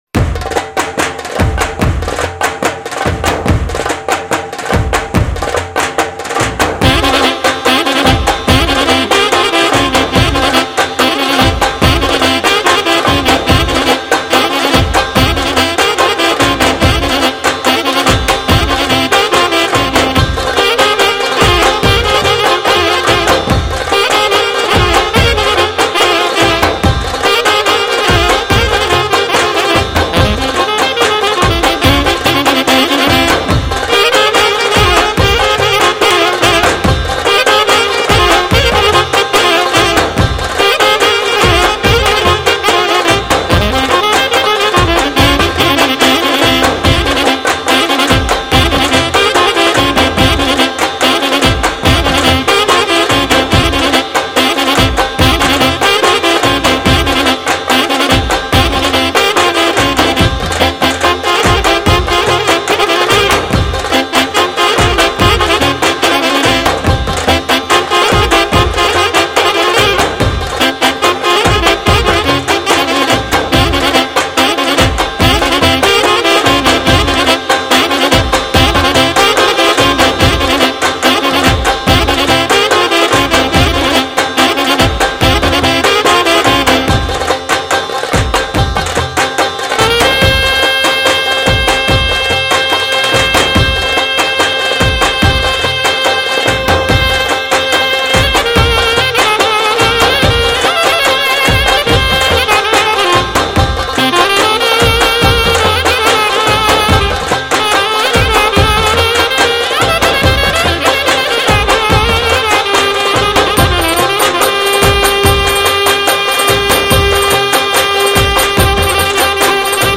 Ferusov_Cocek.mp3